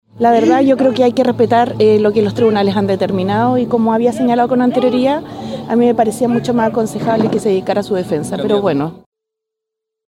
Así, durante el desarrollo del Tedeum Evangélico, Jara respondió recordando que ya había advertido que lo aconsejable era que Jadue se enfocara en su situación judicial, insistiendo en que corresponde respetar las instituciones y los tribunales.